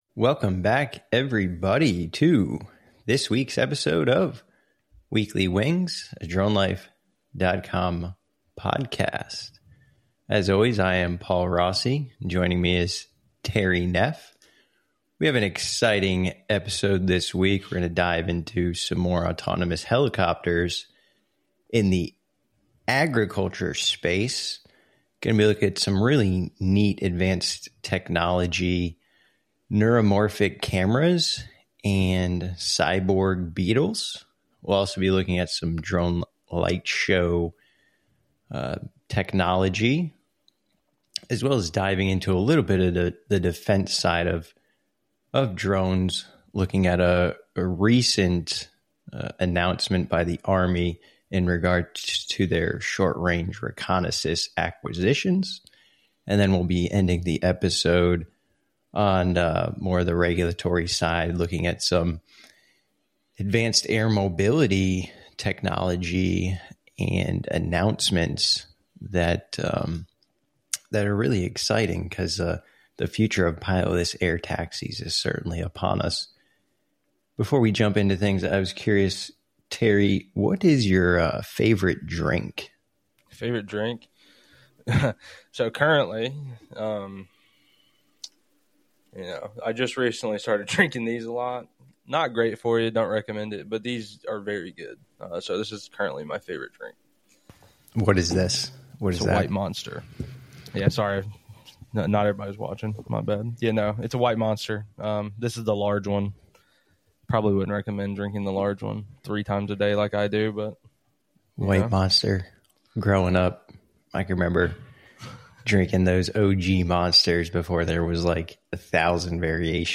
The conversation kicks off with reports of a mysterious drone swarm sighted over Langley Air Force Base.